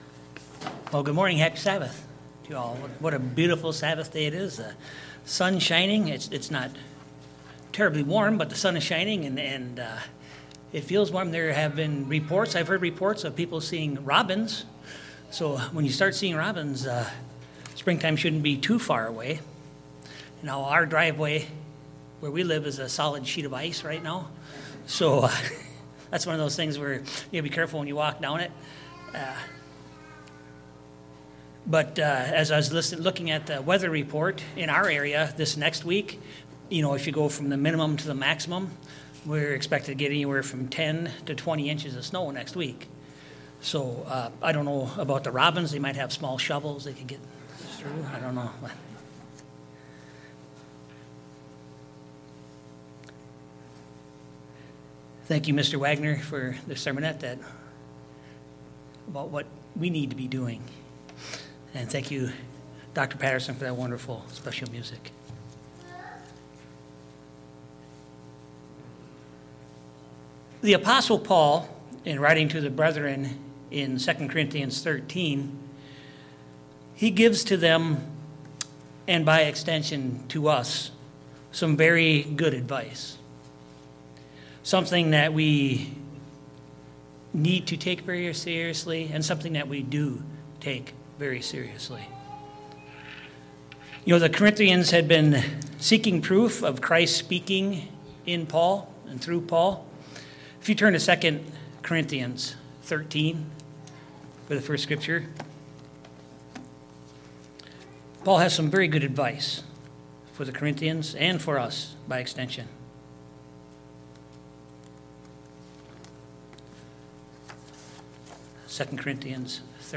Given in Eau Claire, WI